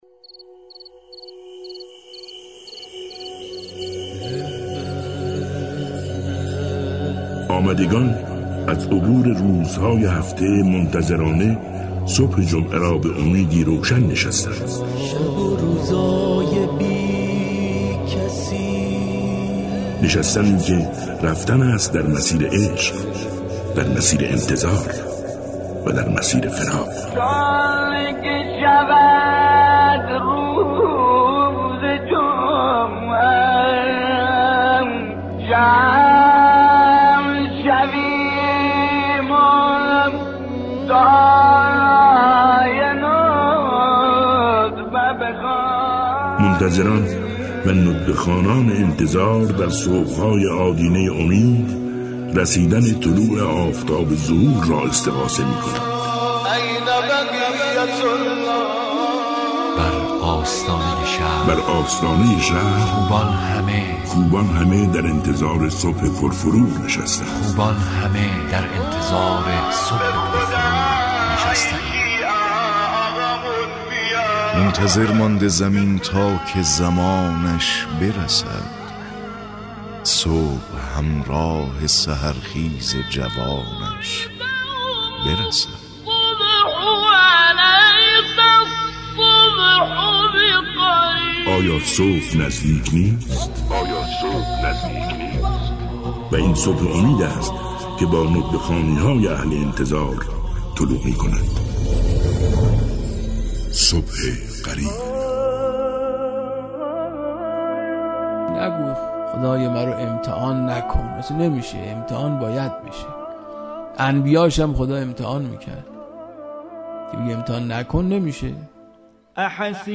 قسمت سیزدهم از مجموعه سخن آوای صبح قریب، مروری بر معارف دعای ندبه... با عنوان «زیبایی امتحانات الهی (2)»